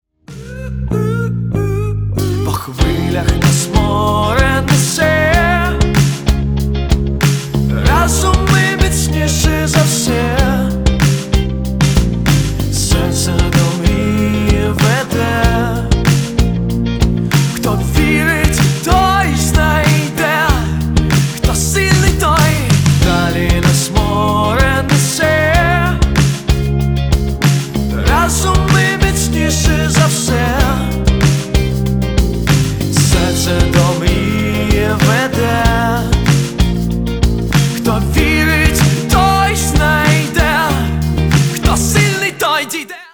• Качество: 320, Stereo
мужской вокал
Драйвовые
мелодичные
спокойные
украинский рок